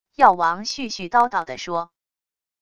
药王絮絮叨叨地说wav音频